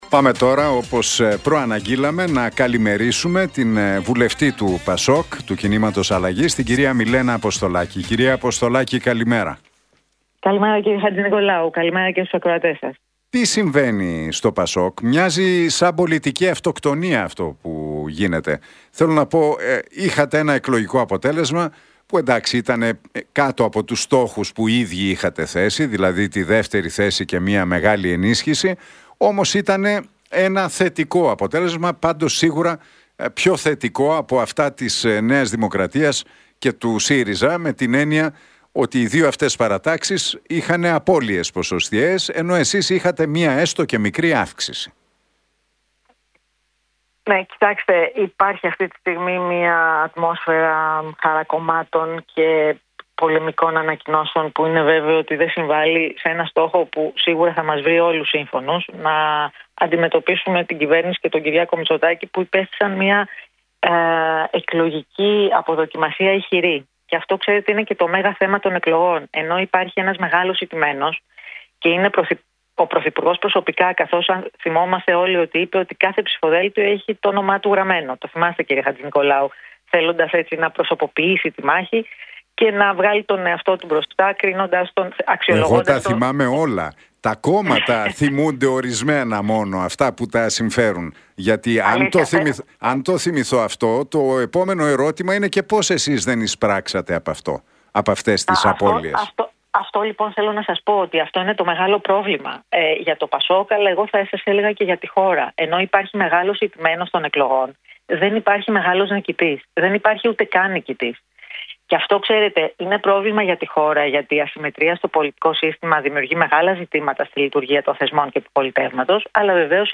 Για τις εξελίξεις στο ΠΑΣΟΚ – ΚΙΝΑΛ μίλησε η βουλευτής Μιλένα Αποστολάκη στον Realfm 97,8 και τον Νίκο Χατζηνικολάου.